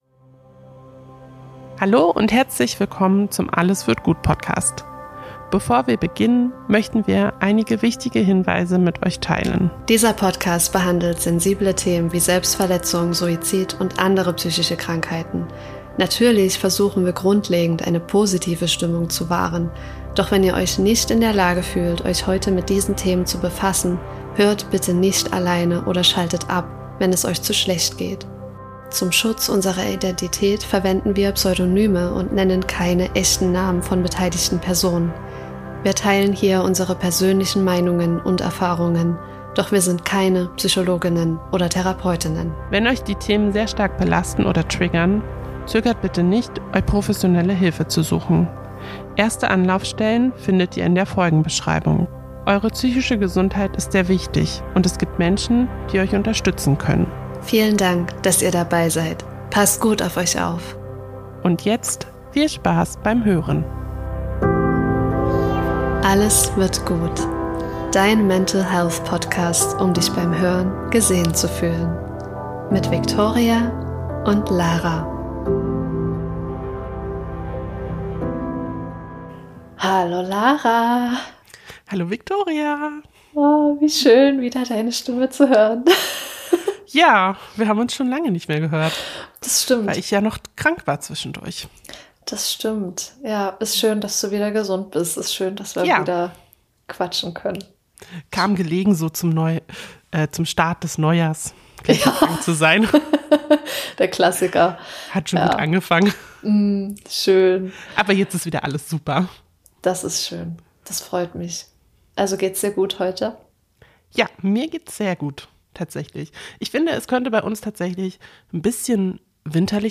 Wie immer sprechen wir hier ungefiltert und ehrlich über unsere Erfahrungen und Meinungen, lachen viel, teilen noch mehr und freuen uns ganz riesig über jeden und jede einzelne von euch, die uns zuhört.